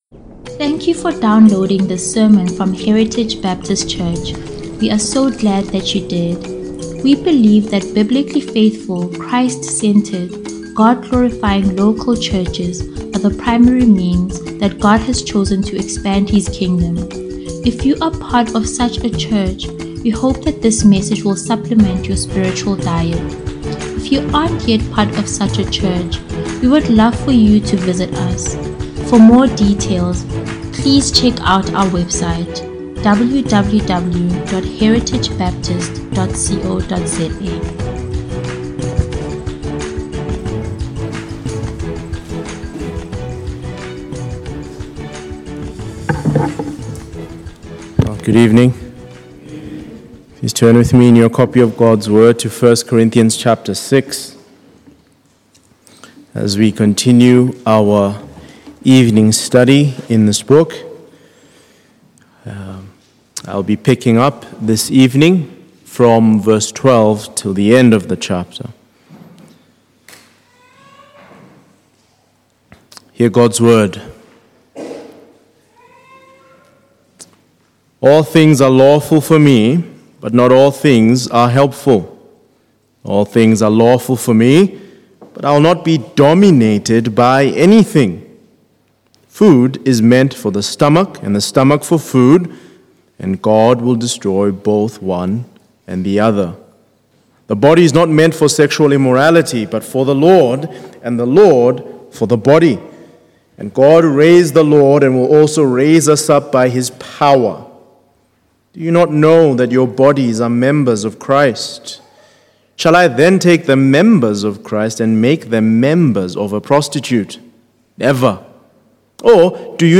Heritage Sunday Sermons